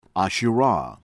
ASHTIANI, SAKINEH MOHAMMADI sah-keh-NEH    moh-hah-mah-DEE    ahsh-tee-ah-NEE